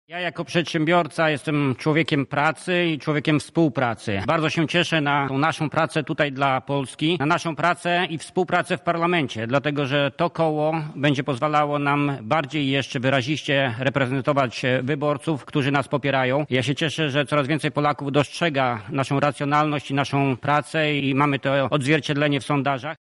O tym, co umożliwi utworzenie formacji mówi senator Jacek Bury: